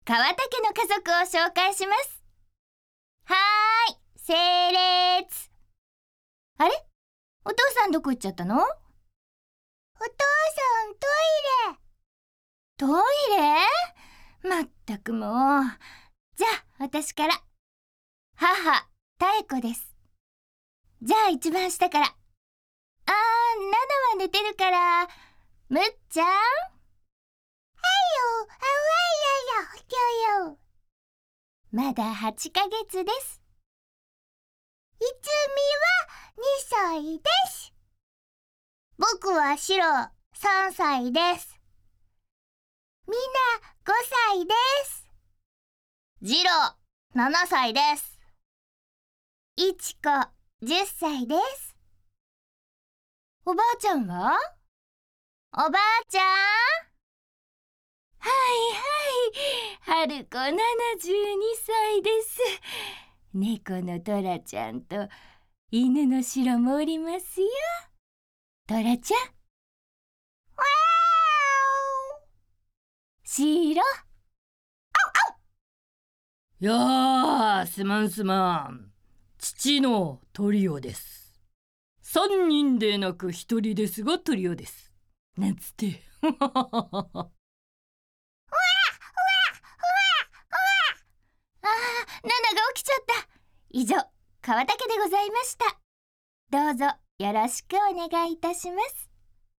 声優　川田妙子【FanFunGarden】
ボイスサンプル1 CM編
Re_Voicesample_KawataTaeko_1_A.mp3